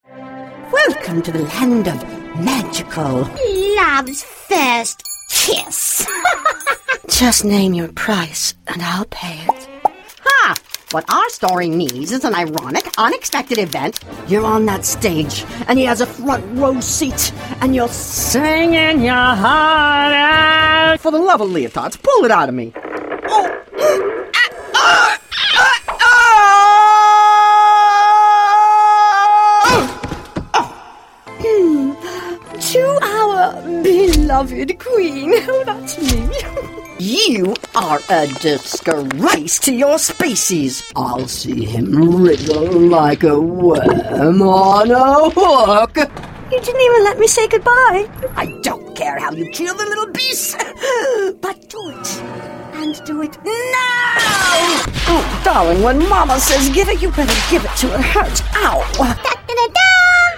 rich, warm, smooth, soothing, calming, sultry, confident, and comforting
Sprechprobe: Sonstiges (Muttersprache):